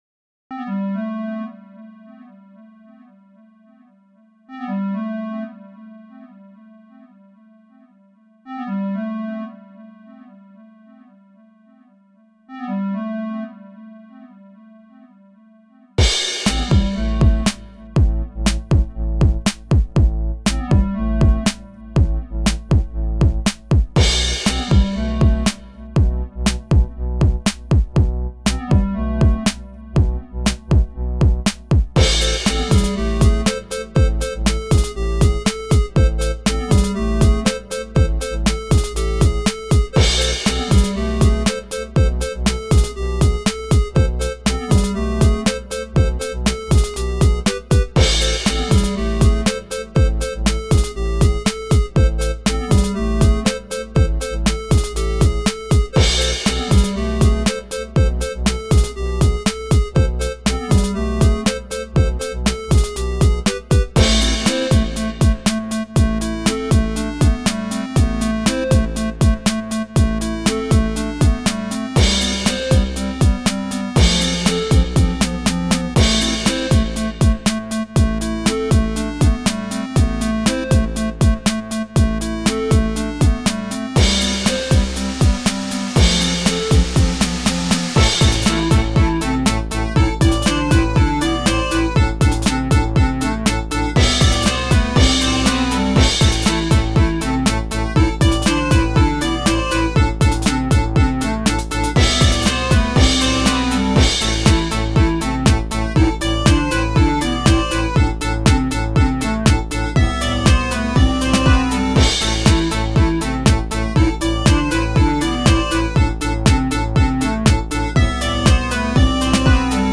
Первый Трек на Elektron Digitone
Это мой профиль в Спотифай, добавляйте в подписки =)) Там демка на три трека есть уже: Собственно трек с Дигитона.